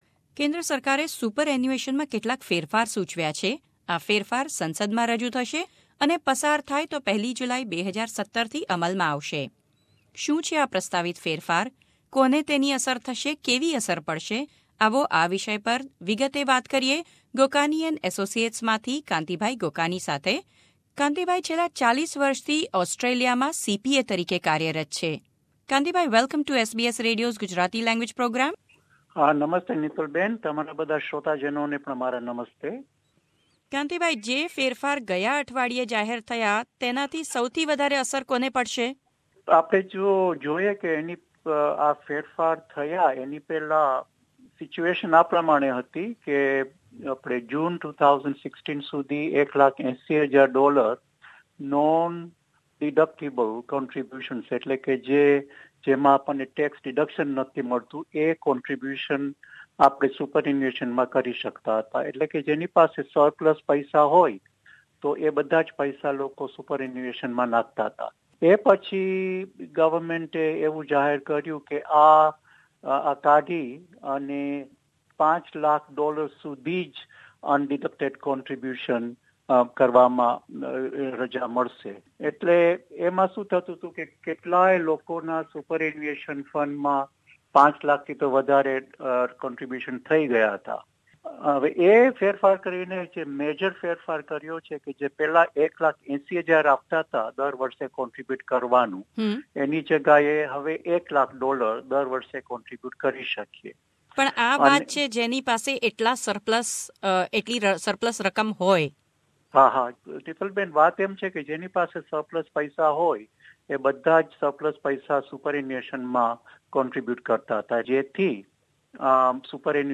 કરેલ ચર્ચા